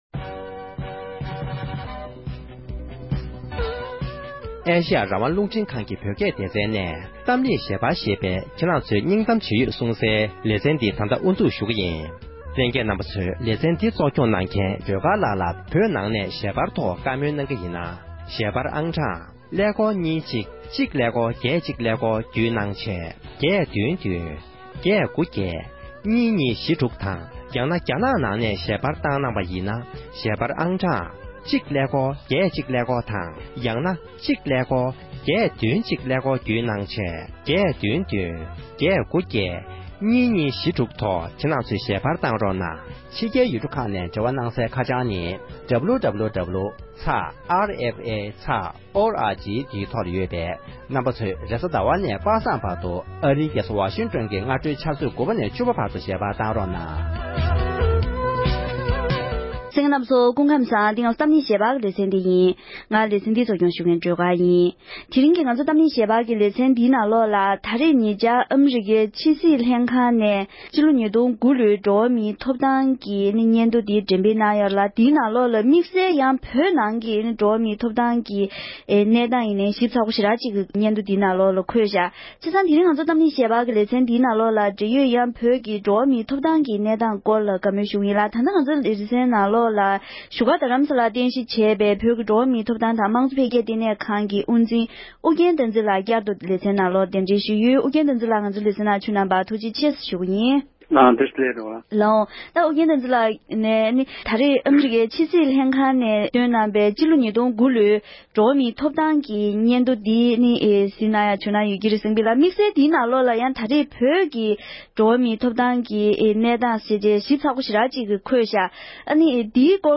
དེ་རིང་གི་གཏམ་གླེང་ཞལ་པར་གྱི་ལེ་ཚན